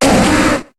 Cri de Cochignon dans Pokémon HOME.